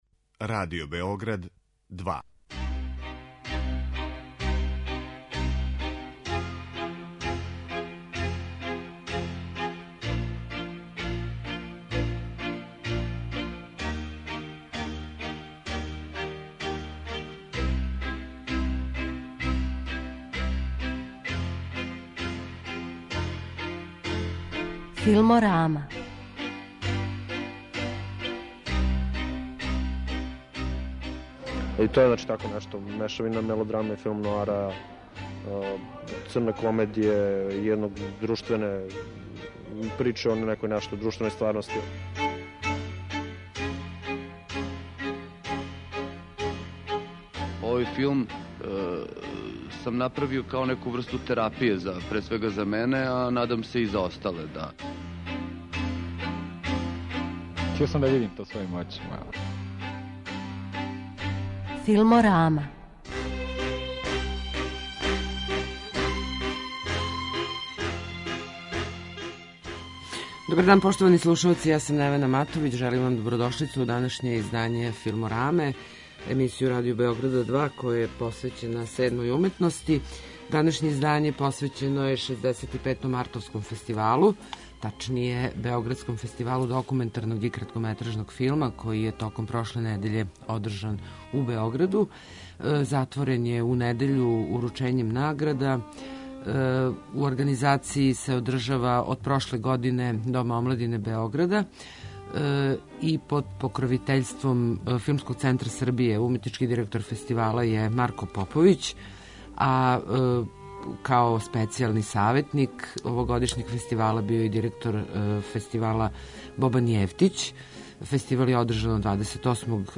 Емисија о филму